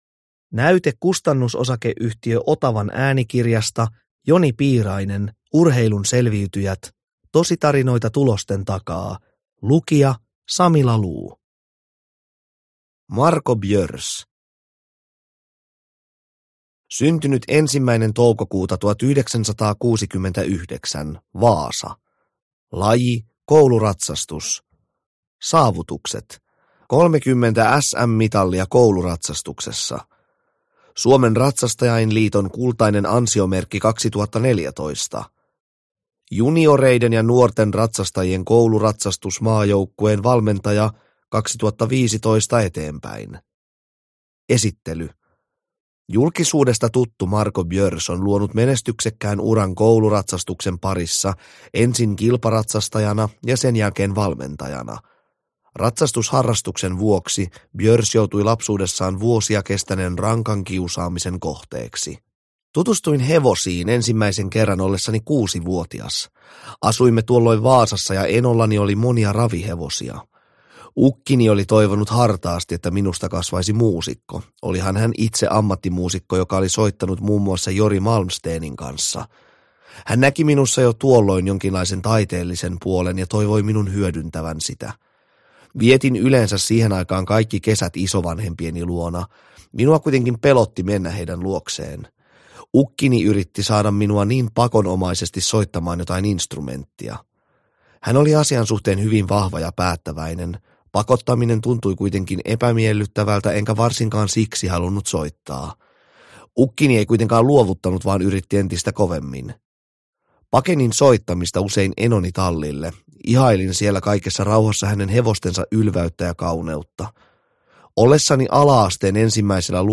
Urheilun selviytyjät (ljudbok